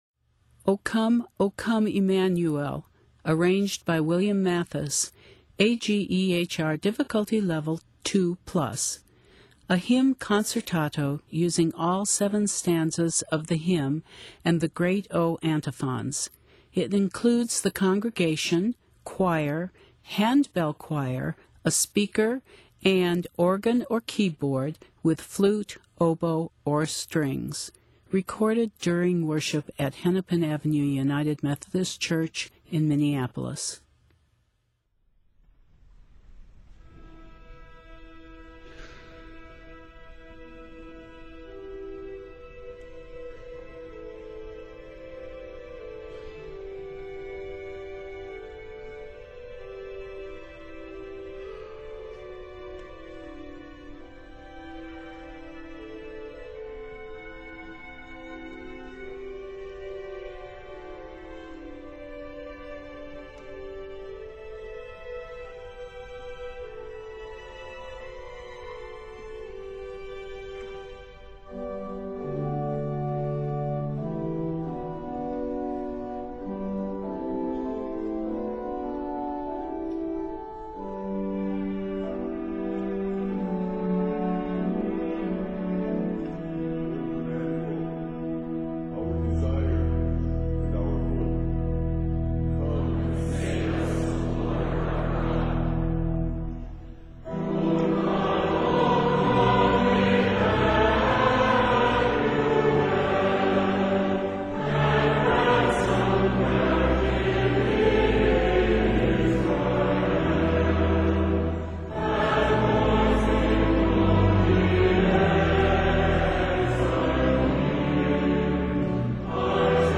hymn concertato
Set in e minor and f minor, measures total 172.